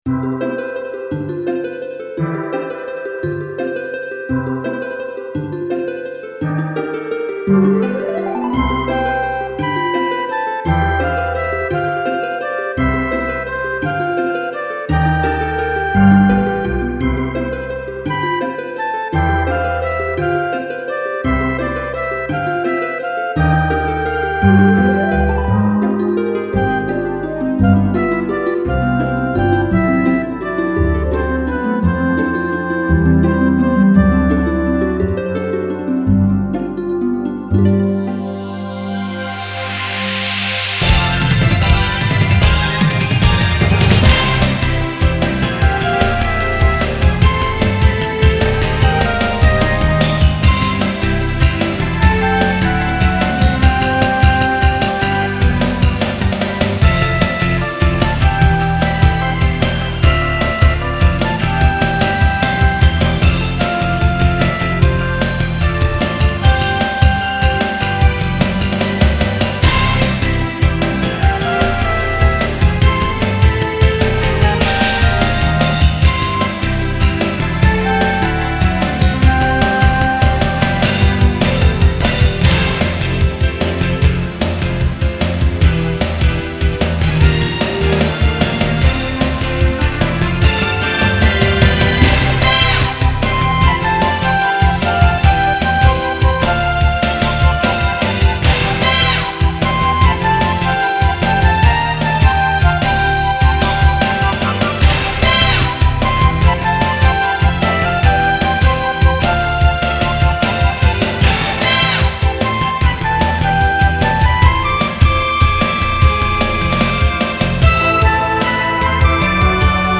(mp3)  　メインテーマ (SC-88pro仮版)